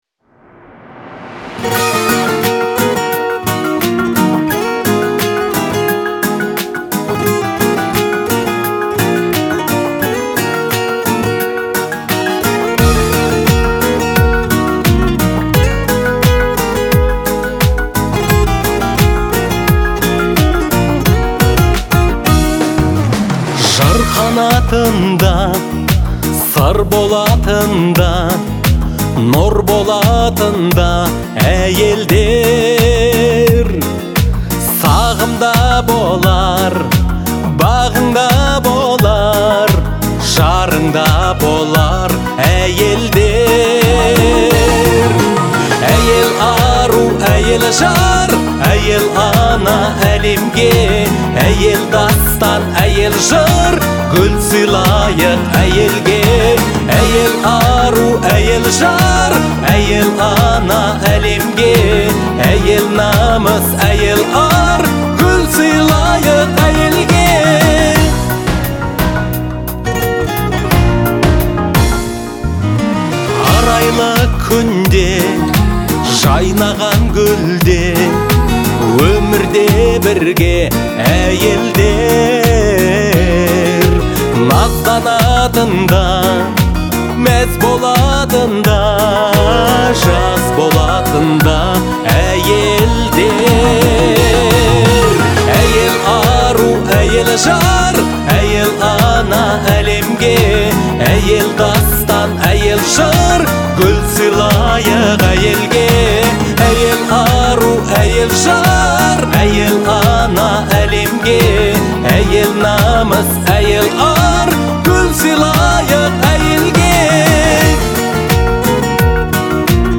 это трогательная казахская песня в жанре поп-фолк